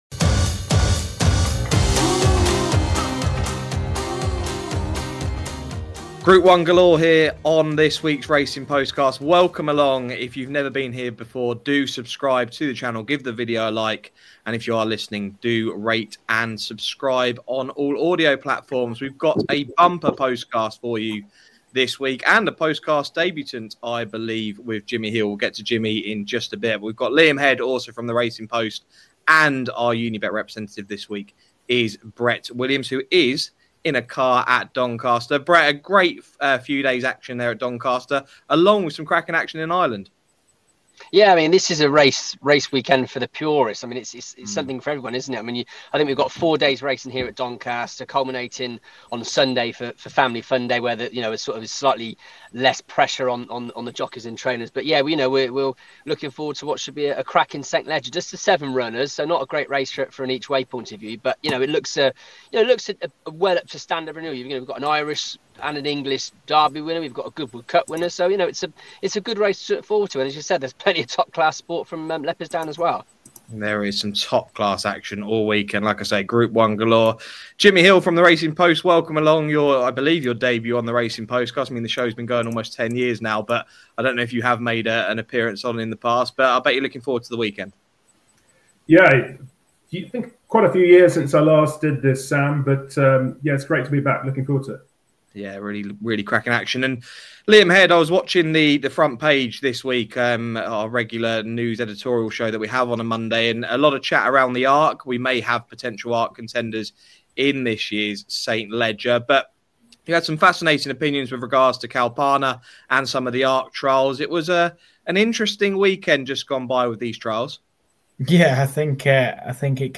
Welcome to the latest episode of the Racing Postcast as our expert panel look forward to an exciting weekend ahead at Doncaster for the Betfred St Leger and the Irish Championship where they preview the ITV action.